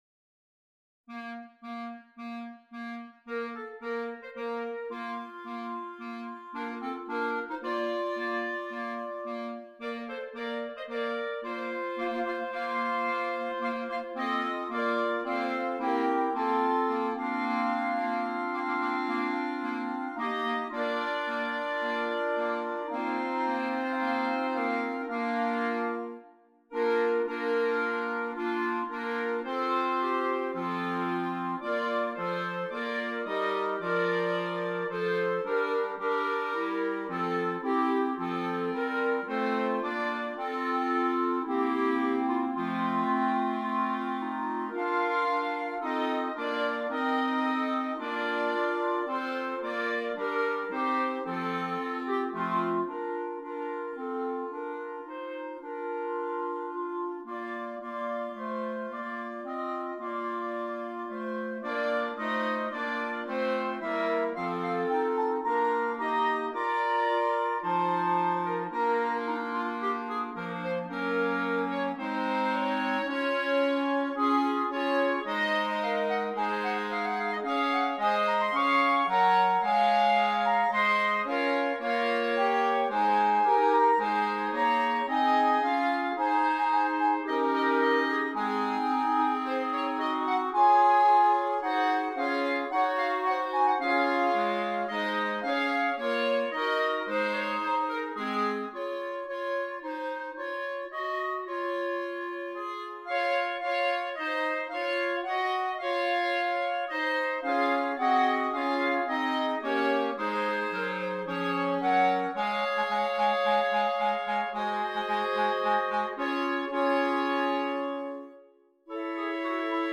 6 Clarinets
Traditional Carol